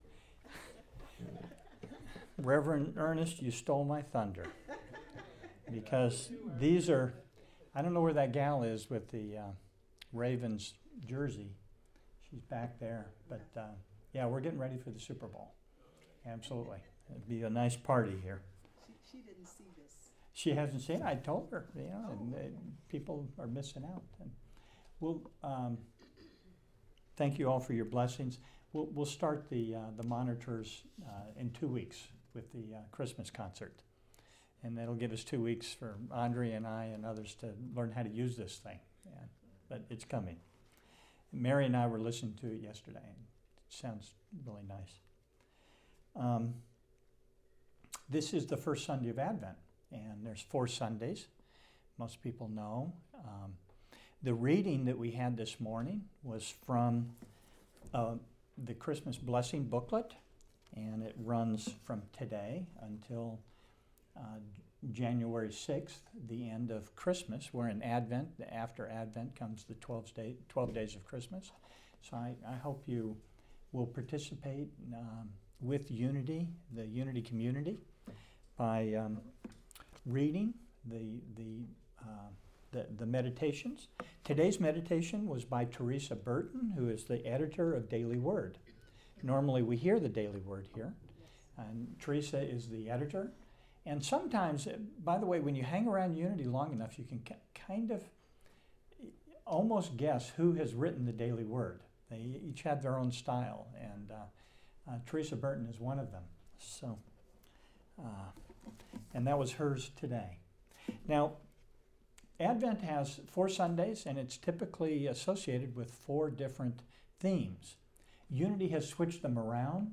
Sunday lesson given at Unity Center of Christianity in Baltimore, December 1, 2019.